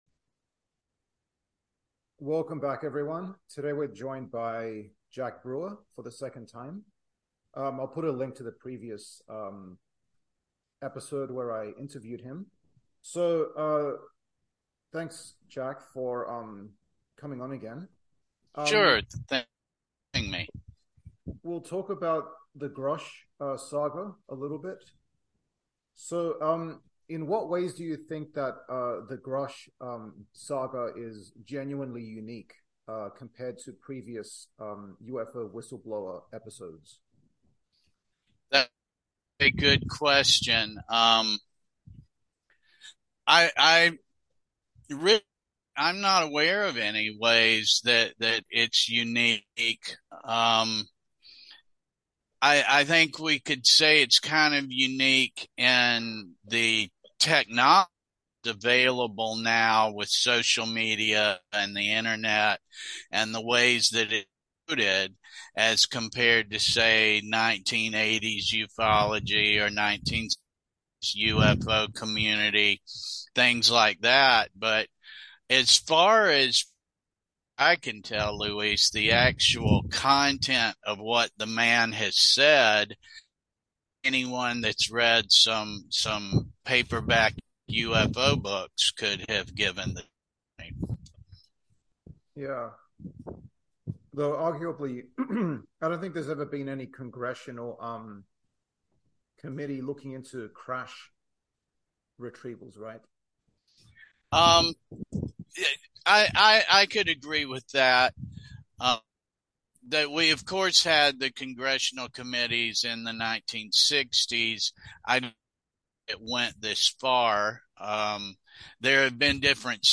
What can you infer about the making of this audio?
Zoom interview